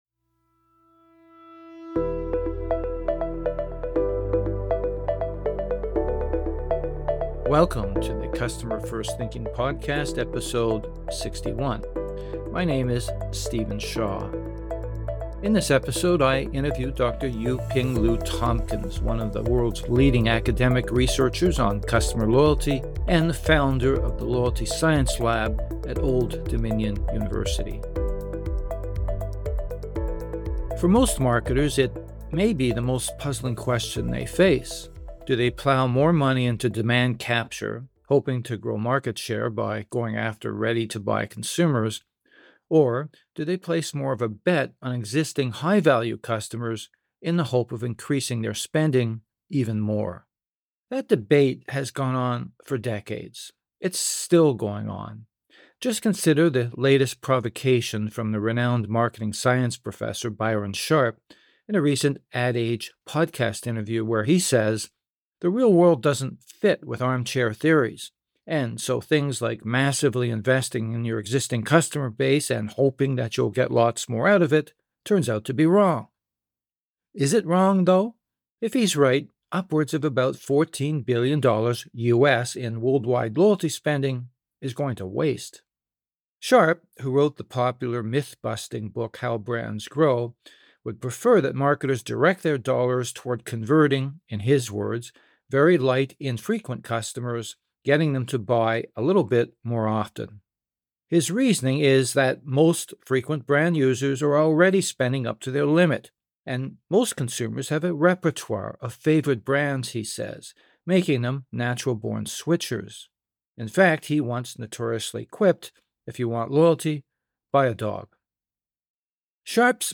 This interview has been edited for length and clarity.